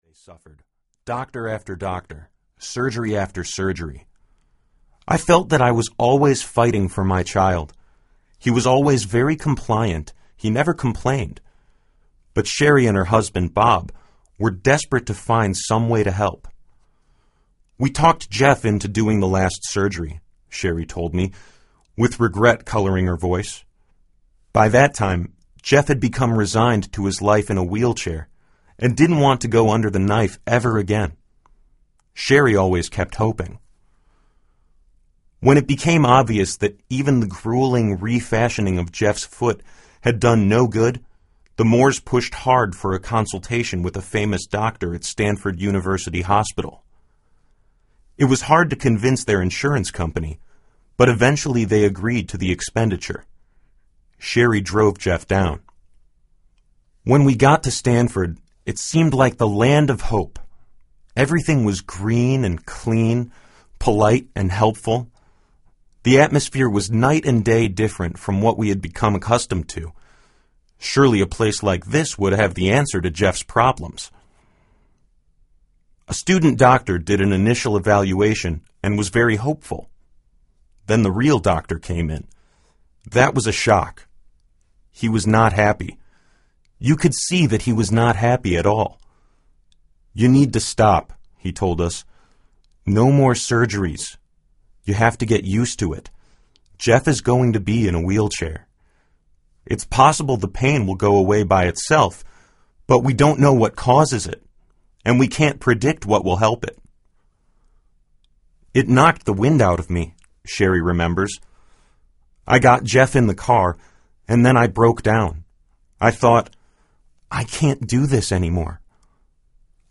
Miracles Audiobook
Narrator
6.35 Hrs. – Unabridged